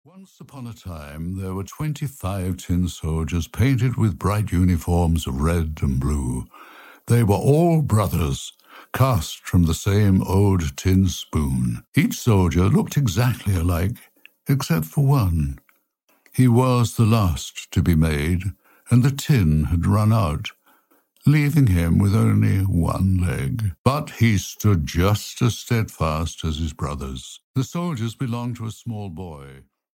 The Steadfast Tin Soldier (EN) audiokniha
Ukázka z knihy
Sir Roger Moore narrates "The Steadfast Tin Soldier," which tells the story of one tin soldier, who despite being exactly the same as all of his many brothers, finds himself setting out alone, by accident, on a series of adventures. Thankfully, this little tin soldier is afraid of nothing and will bravely face every trial on his way.Sir Roger Moore was a British actor, most famous for portraying secret agent James Bond in seven films, including "Live and Let Die".
• InterpretSir Roger Moore